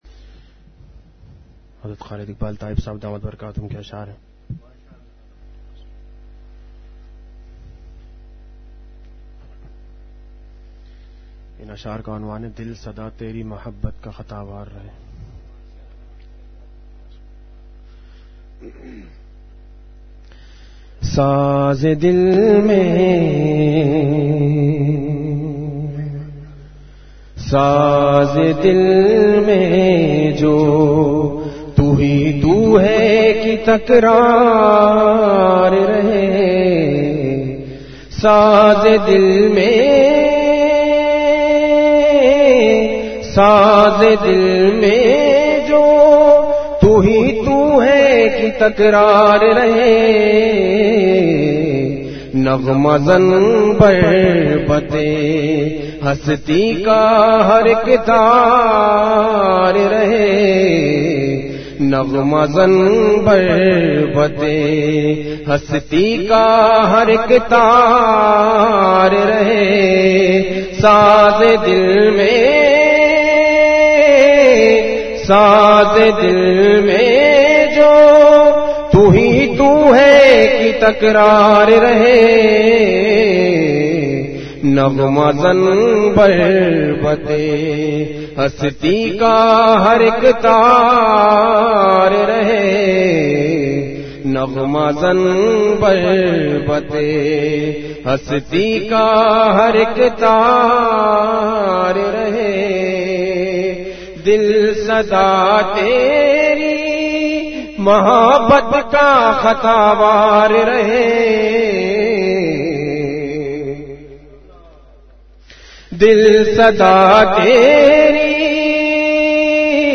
اشعار کے بعد مختصر بیان بھی فرمایا۔